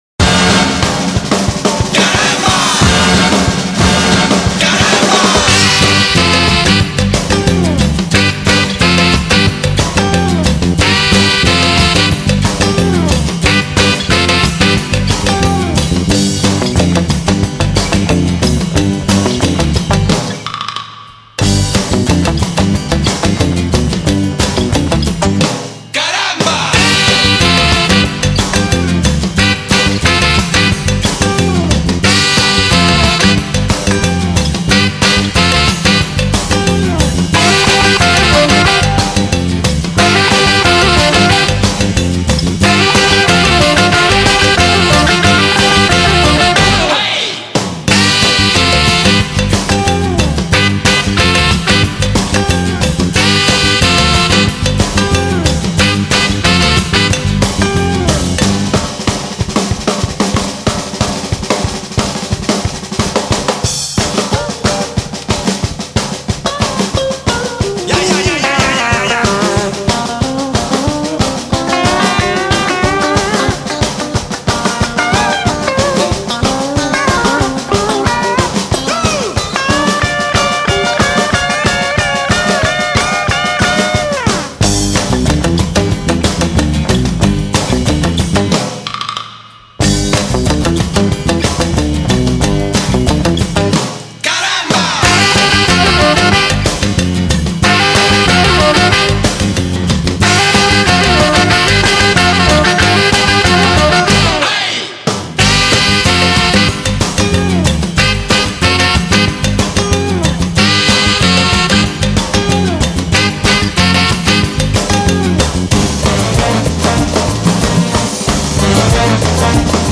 【音乐类型】：纯音乐专辑5CD
门金曲，旋律性强而富有动感，散发着无穷的时尚气息。